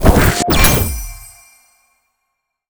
spell_harness_magic_06.wav